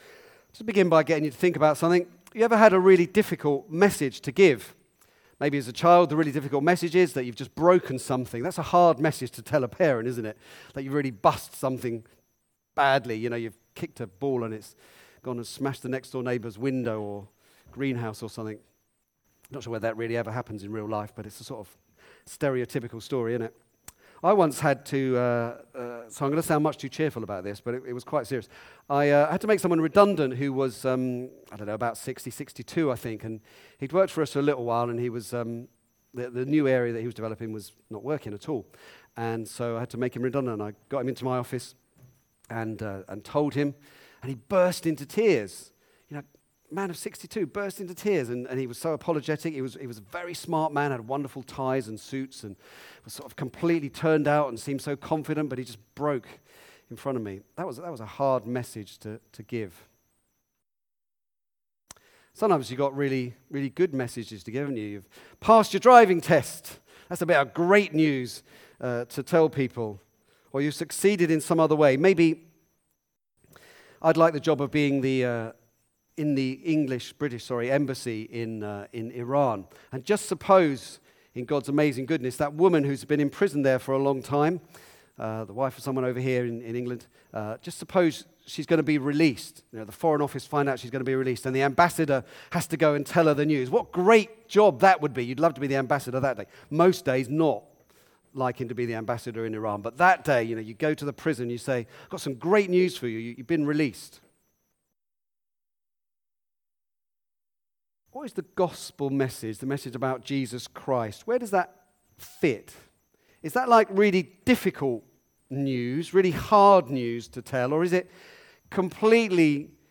Back to Sermons The God who reasons